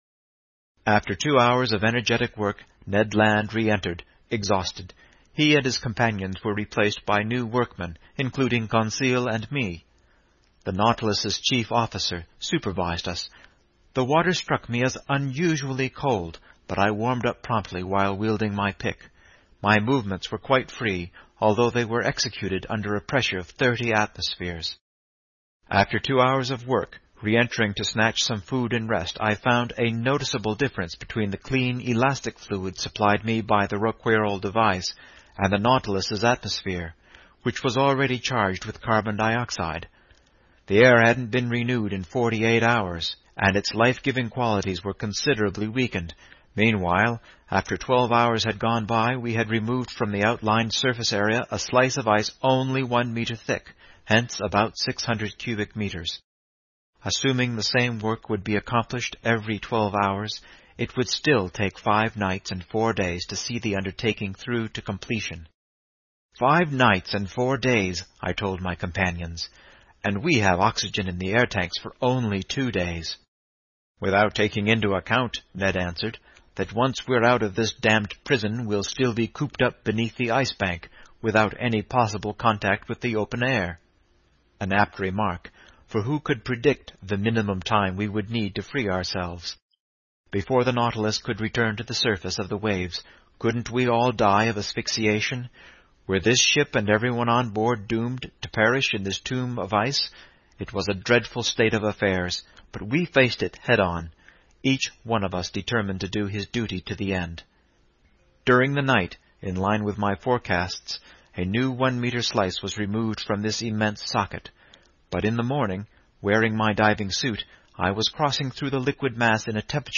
英语听书《海底两万里》第471期 第29章 缺少空气(3) 听力文件下载—在线英语听力室
在线英语听力室英语听书《海底两万里》第471期 第29章 缺少空气(3)的听力文件下载,《海底两万里》中英双语有声读物附MP3下载